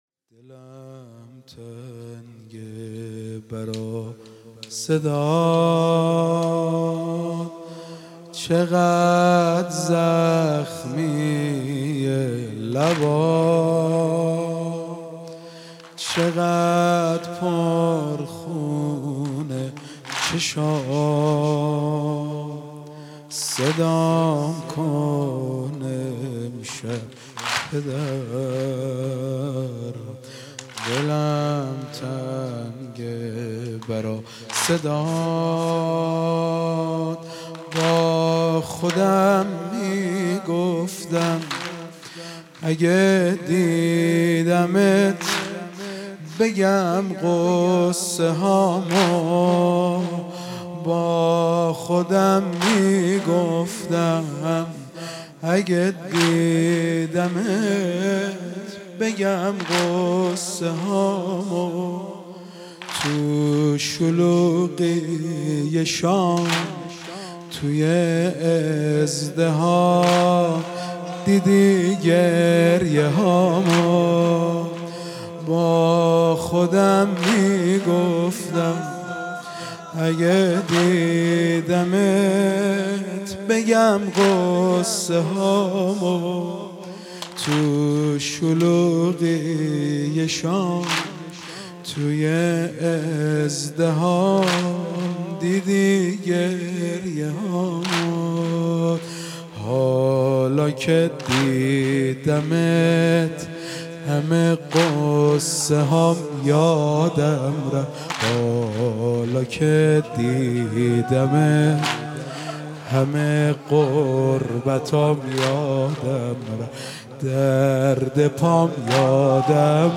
مداحی شب سوم محرم
در هیئت عبدالله بن الحسن